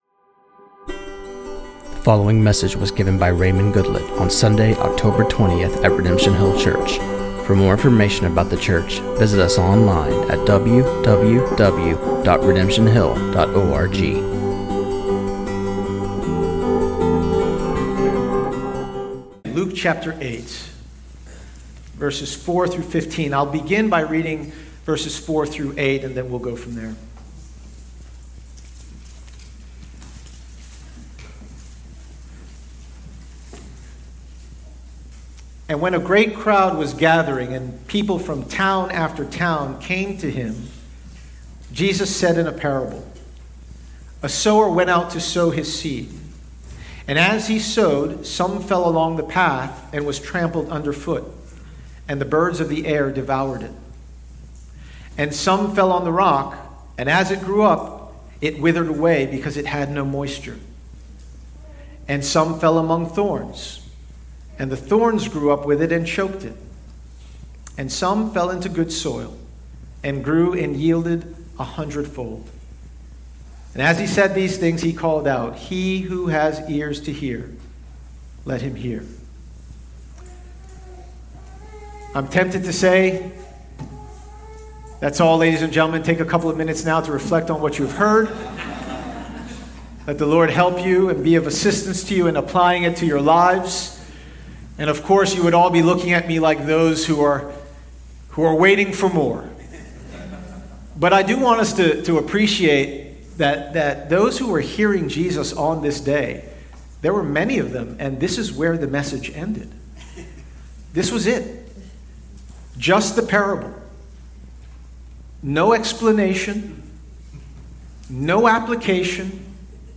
This sermon on Luke 8:4-15